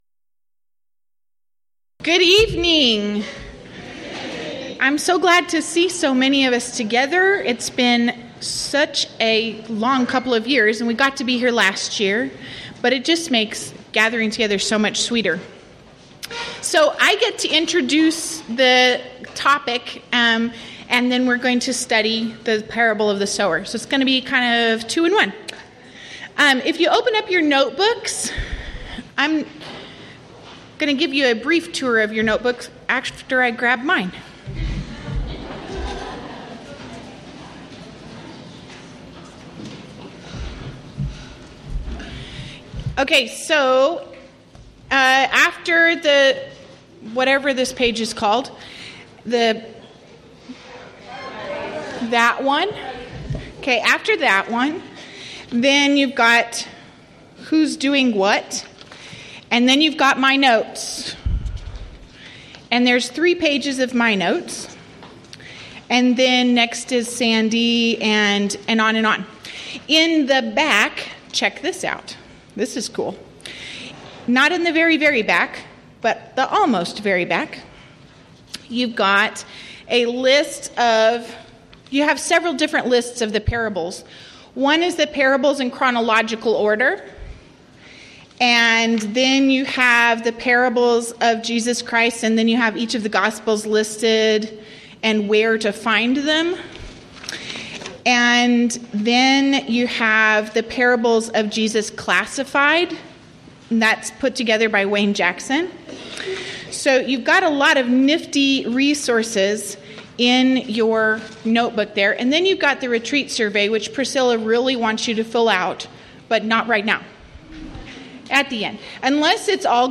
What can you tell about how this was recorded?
Event: 9th Annual Texas Ladies in Christ Retreat Theme/Title: Studies in Parables